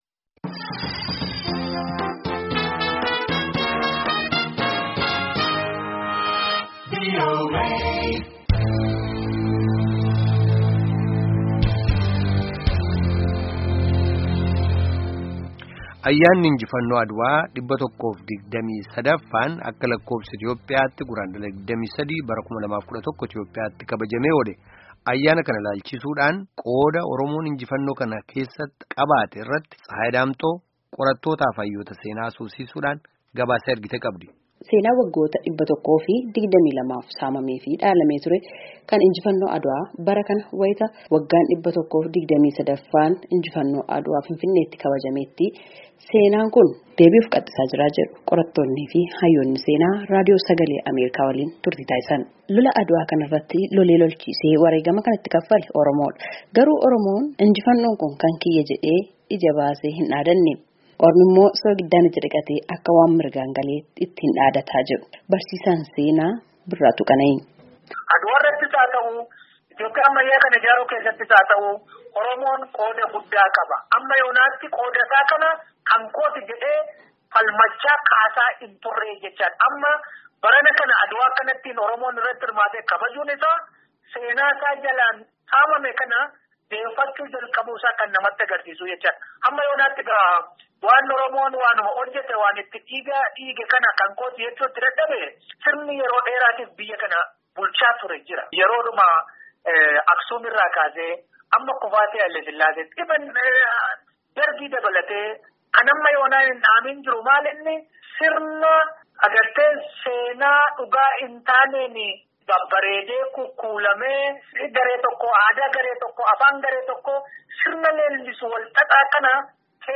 qorattoota fi hayyoota seenaa haasofsiisuun gabaasaa nuuf ergite qabdi.